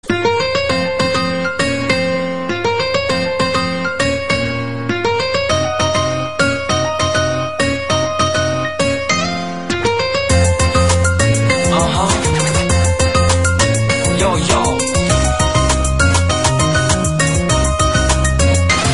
短信铃声